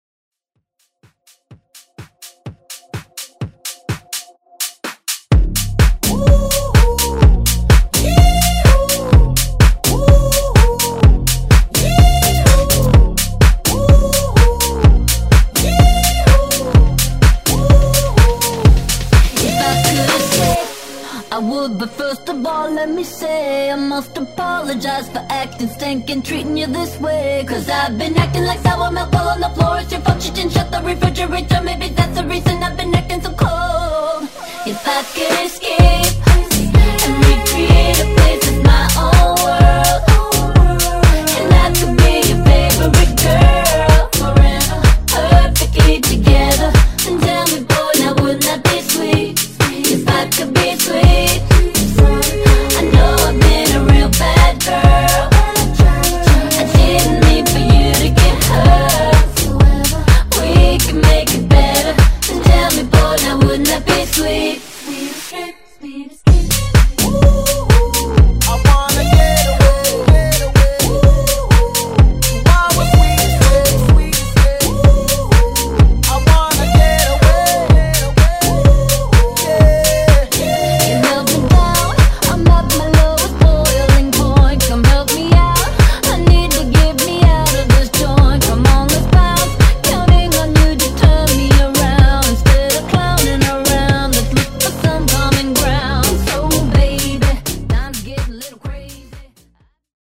Genres: 90's , RE-DRUM
Clean BPM: 104 Time